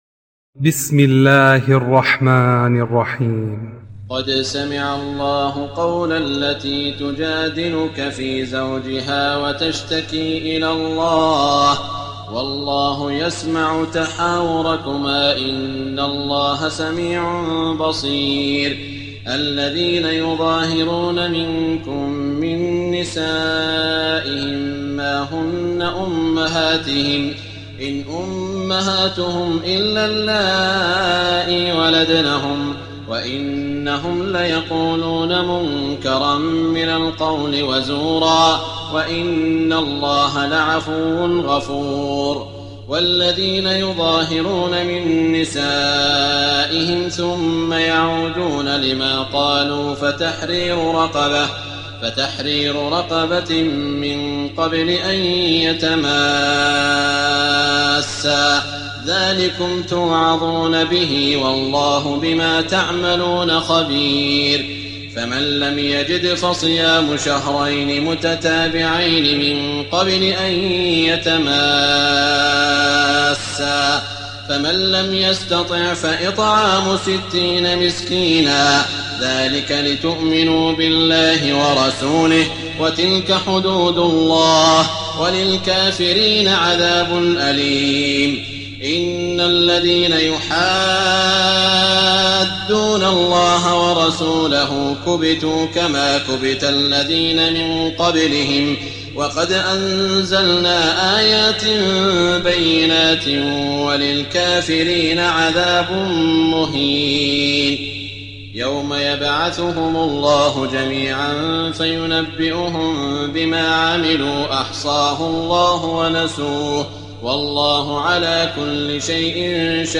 تراويح ليلة 27 رمضان 1419هـ من سور المجادلة الى الصف Taraweeh 27 st night Ramadan 1419H from Surah Al-Mujaadila to As-Saff > تراويح الحرم المكي عام 1419 🕋 > التراويح - تلاوات الحرمين